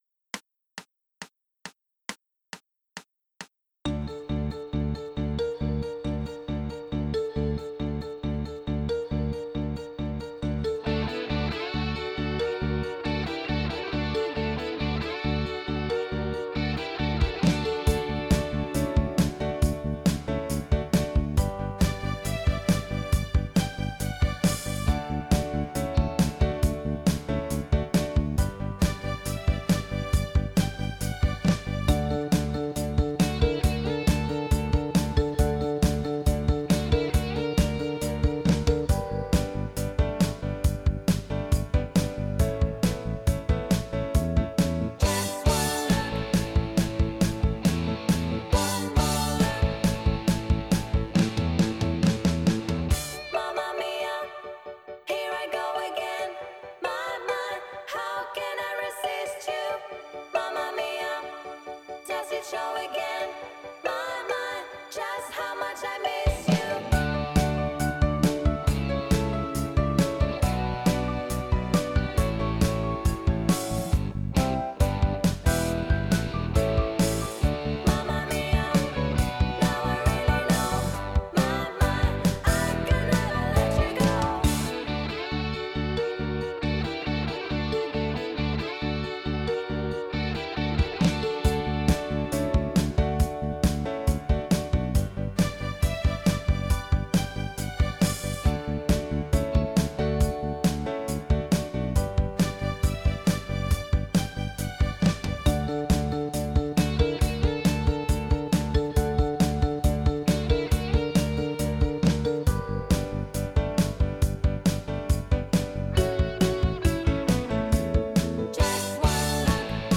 4 VOCALS